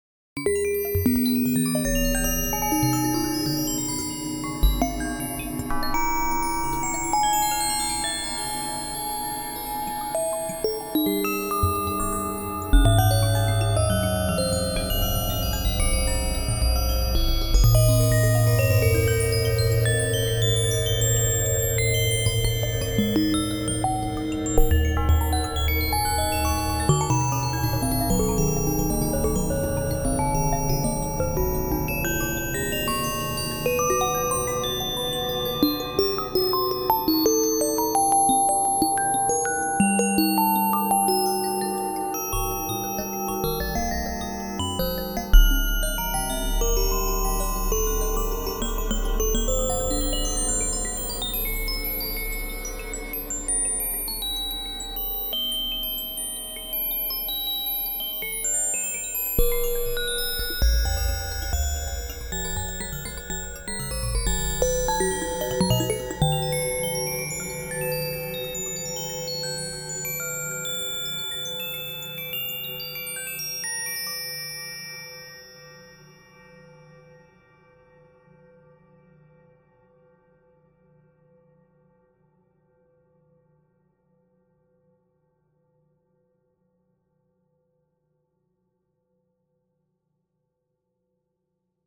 I got a little further along with this additive synth today.